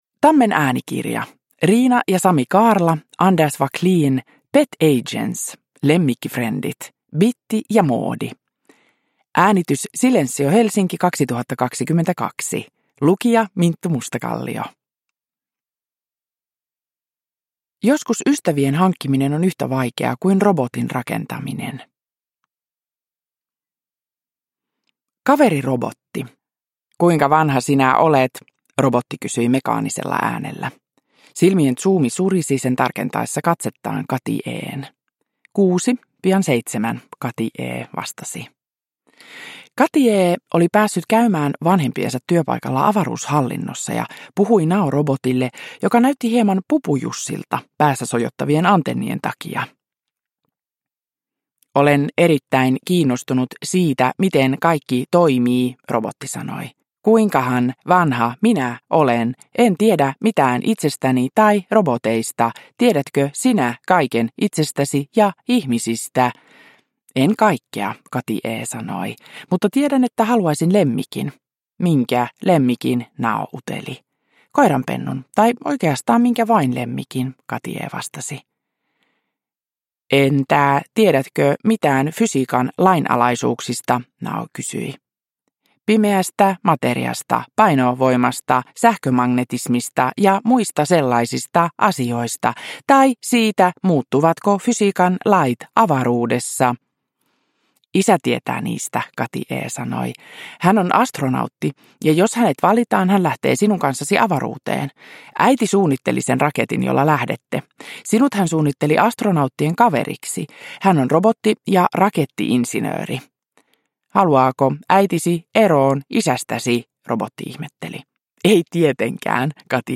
Bitti ja Moodi. Lemmikkifrendit 1 – Ljudbok – Laddas ner
Uppläsare: Minttu Mustakallio